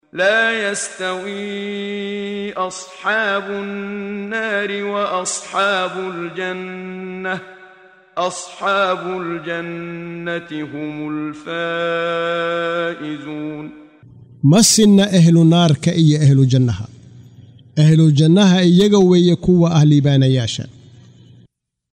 Waa Akhrin Codeed Af Soomaali ah ee Macaanida Suuradda Al-Xashar ( Kulminta ) oo u kala Qaybsan Aayado ahaan ayna la Socoto Akhrinta Qaariga Sheekh Muxammad Siddiiq Al-Manshaawi.